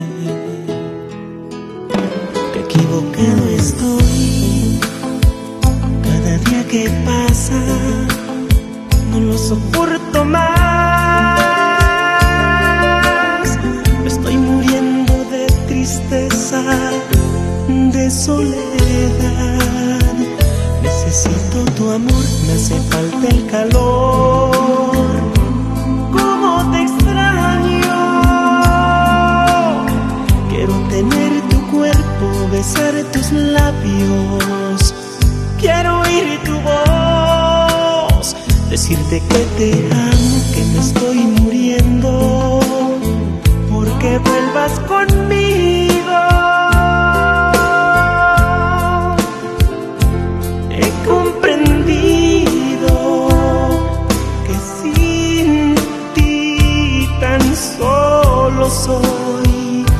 Suenan Exelente!